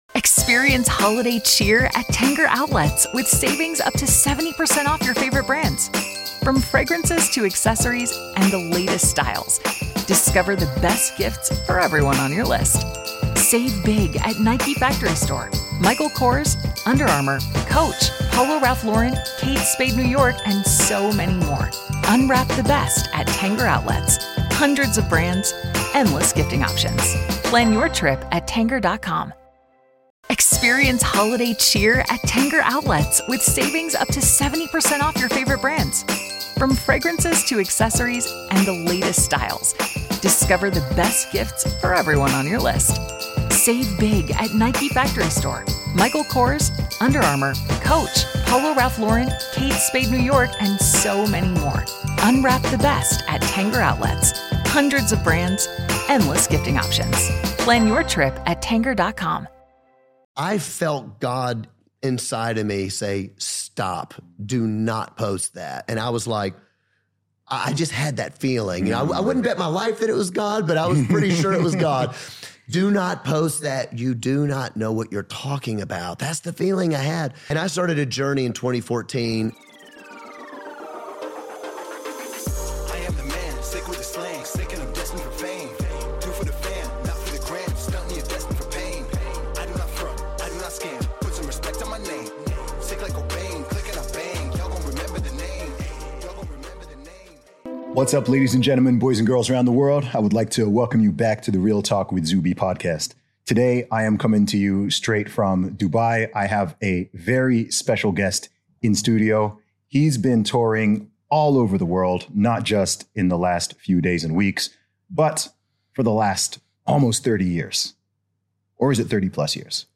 In this exclusive interview in Dubai, John sits down with Zuby to discuss his career, cultural shifts in the USA, and how he manages success. The two explore the value of faith and the post-modern 'woke' peak of 2021. John also shares the tipping point that caused him to speak out, the importance of independent media, and the value of discourse.